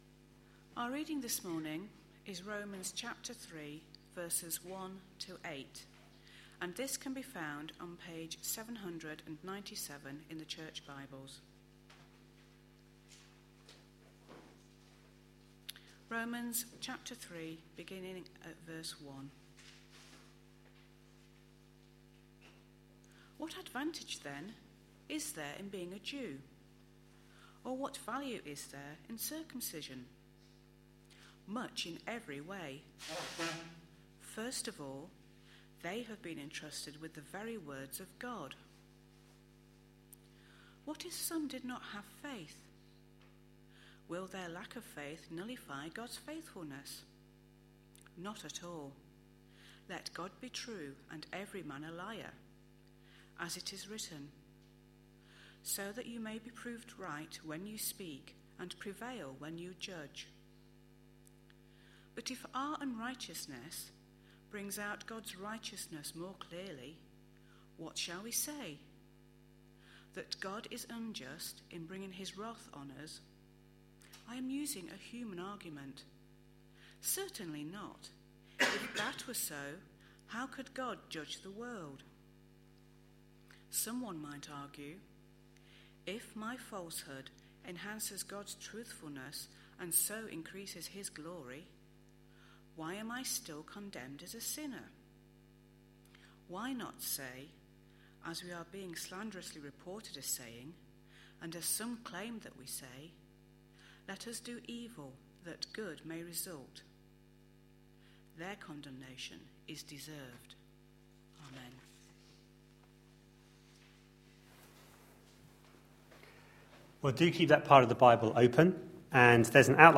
A sermon preached on 28th October, 2012, as part of our Romans series.